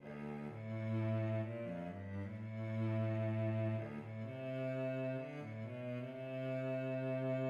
4/4: Poco lento D major – Allegro D minor
Excerpt 4 (cello)